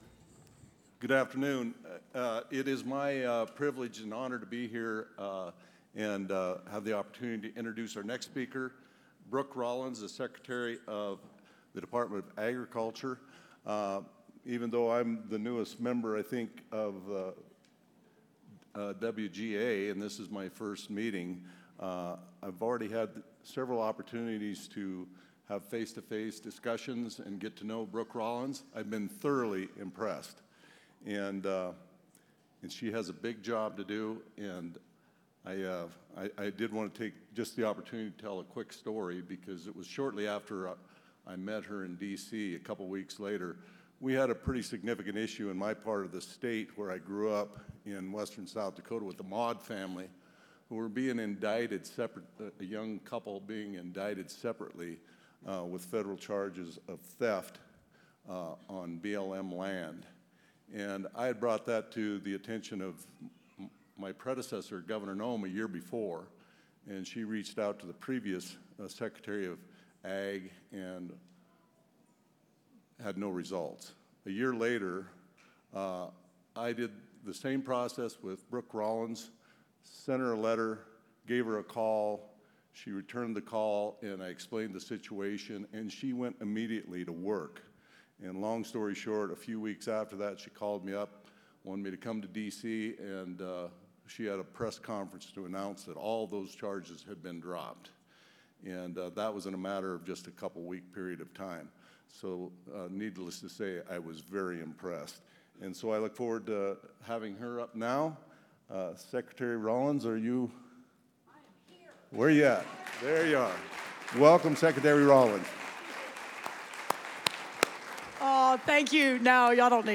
U.S. Department of Agriculture Secretary Brooke Rollins announced Monday she was rescinding the heavily litigated 2001 Roadless Rule. The pronouncement came while state executives met in Santa Fe, New Mexico, for the Western Governors’ Association conference.
Sec.-Rollins-keynote.mp3